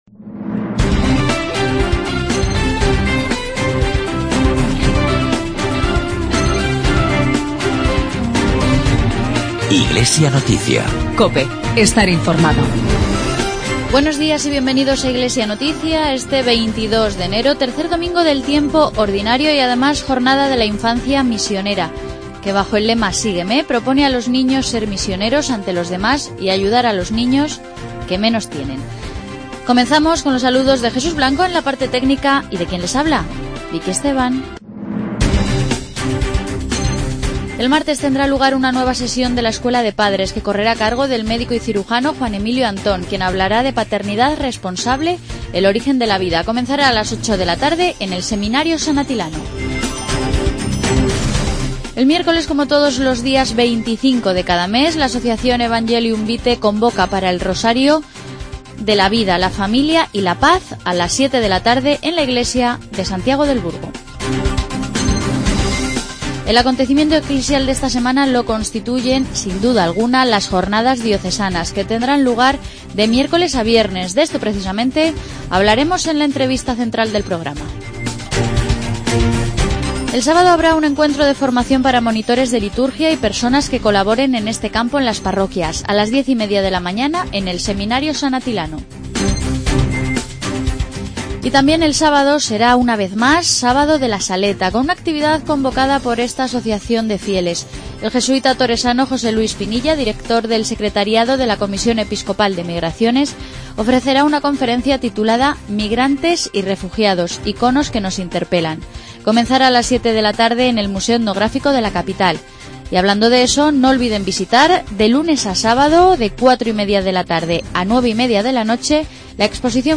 Informativo diocesano.